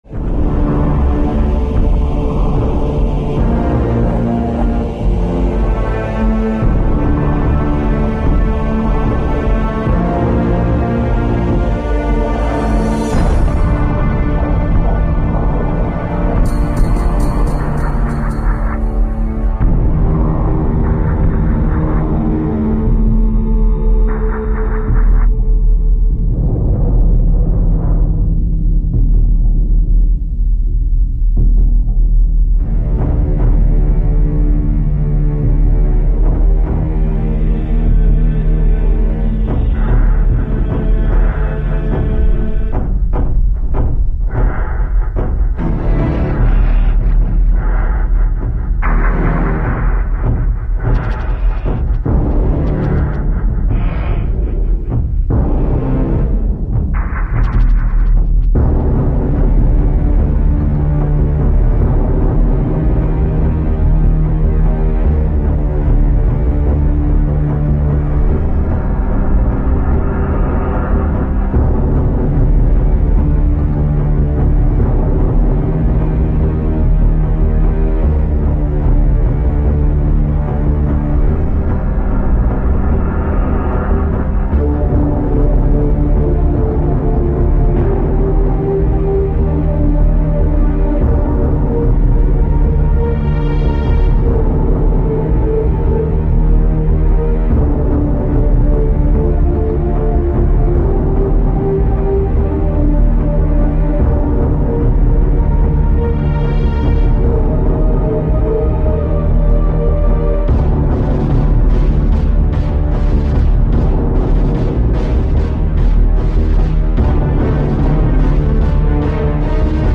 track 07 | name: "orchestral/ambient"
module oc1 and the roland string expander se1.